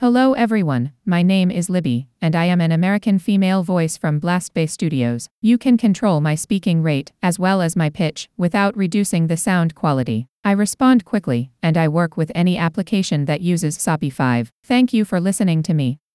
[DECtalk] Formant-based Neural Text to Speech Voices from Blastbay Studios
Libby (English United States)
blastbay_us_libby.wav